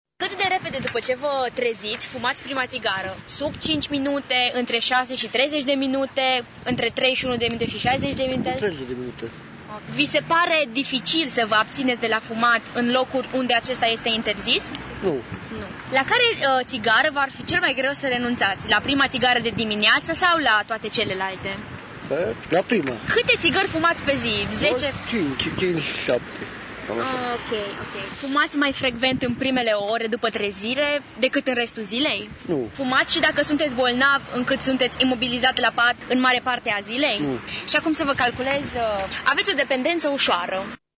Fumătorii aflaţi în trecere s-au lăsat supuşi testului pentru a vedea cât sunt de dependeţi: